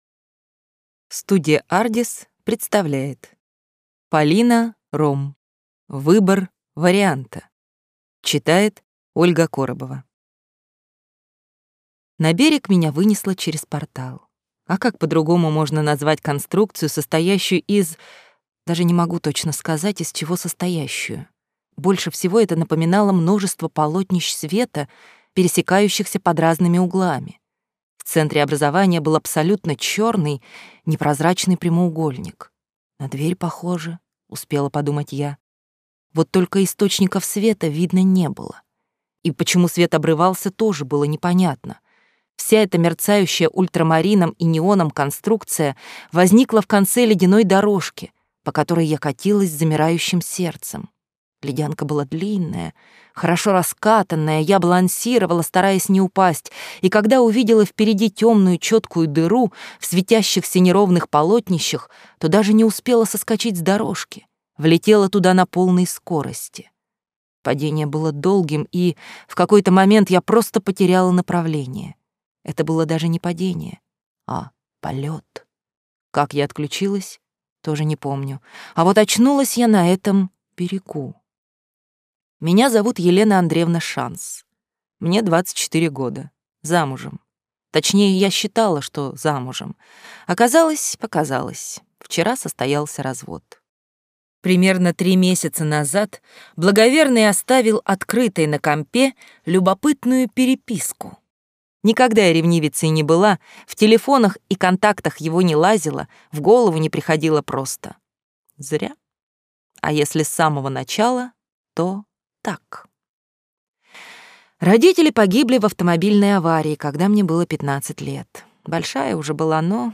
Аудиокнига Выбор варианта | Библиотека аудиокниг